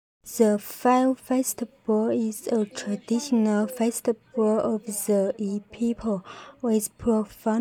Synthèse vocale
Voix off anglaise